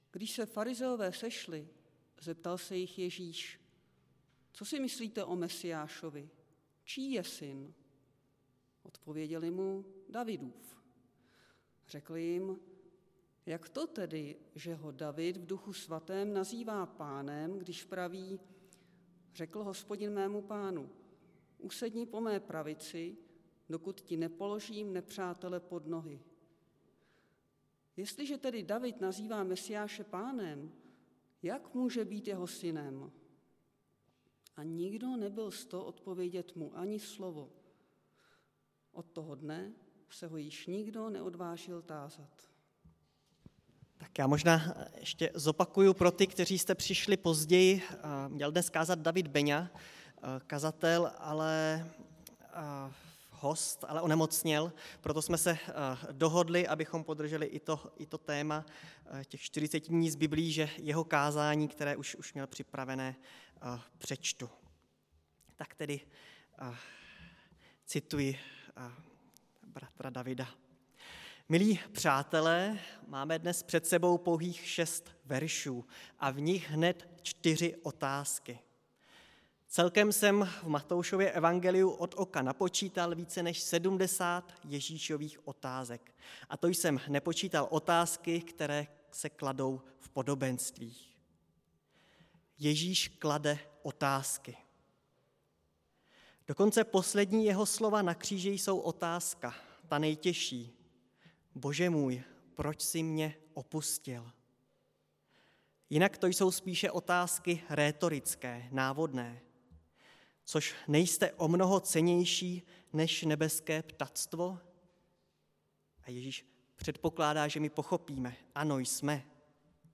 Nedělní kázání – 10.4.2022 Syn Davidův